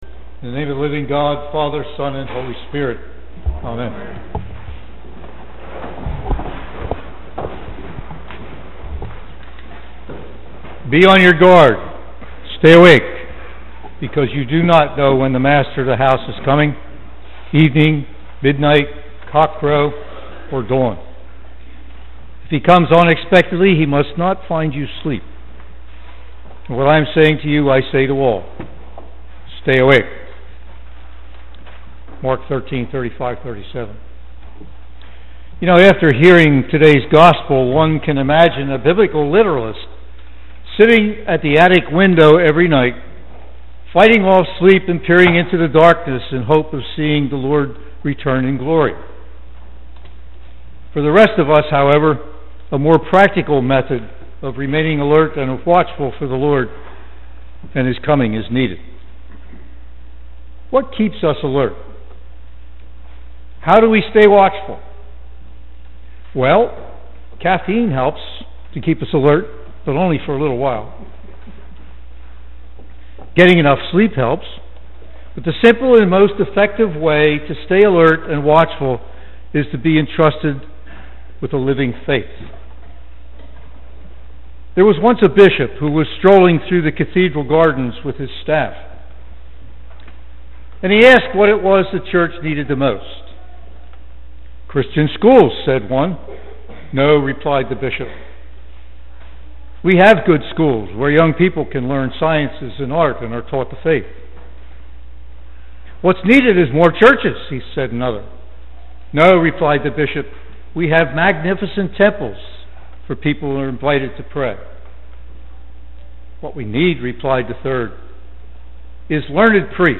Sermon December 1